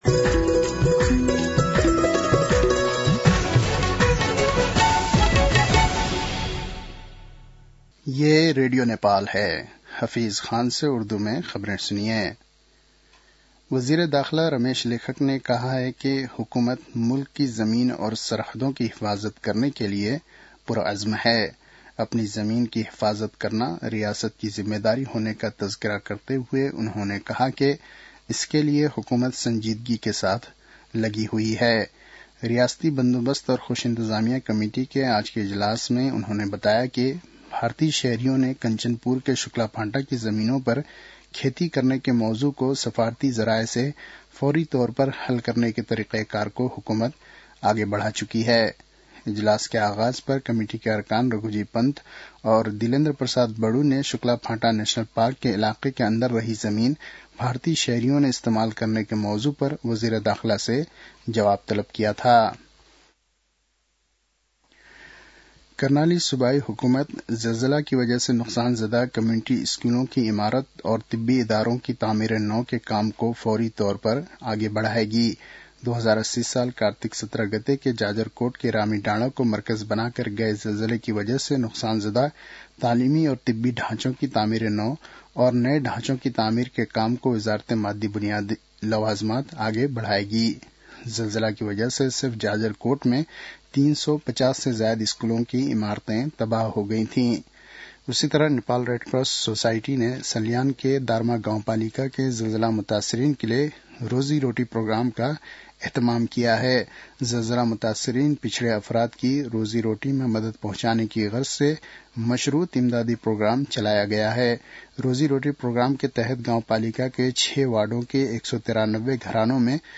उर्दु भाषामा समाचार : २३ पुष , २०८१